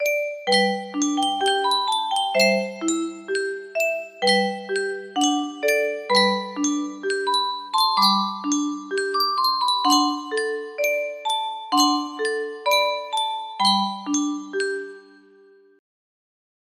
Yunsheng Music Box - Unknown Tune 1108 music box melody
Full range 60